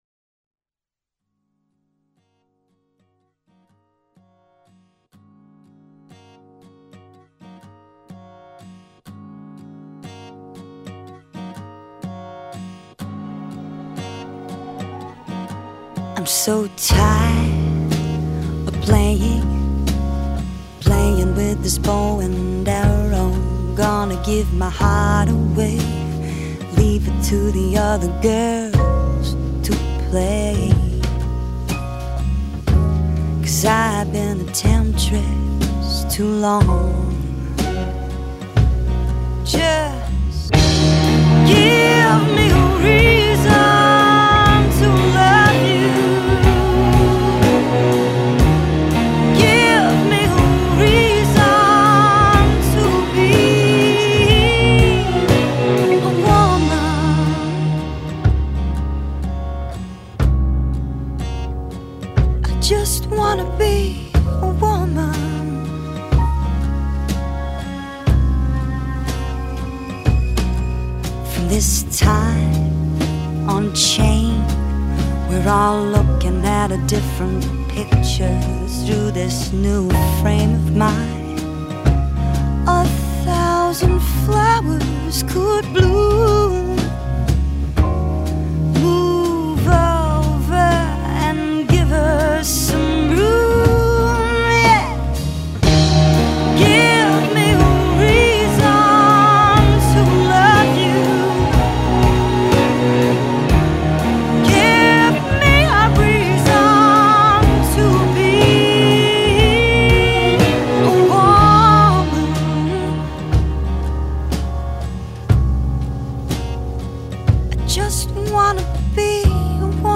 is a killer cover